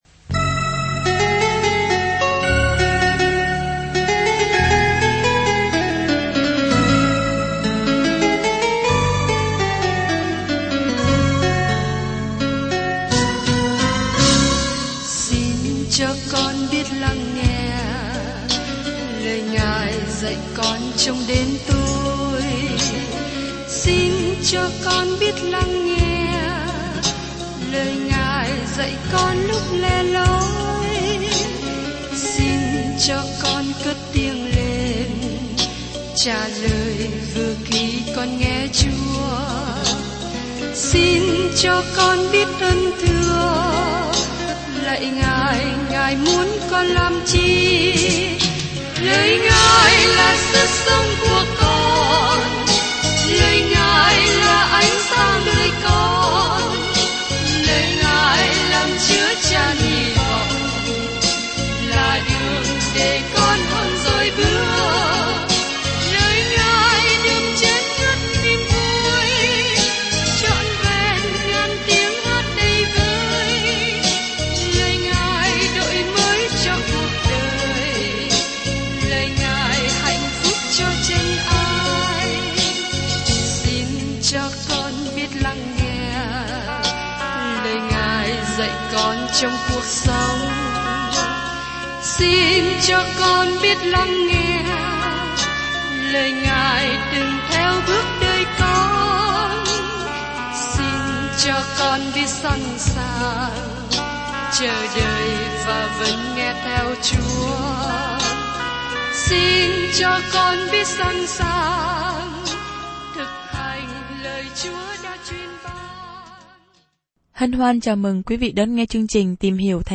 Bài giảng … là một phần của chương trình “Tìm Hiểu Thánh Kinh”.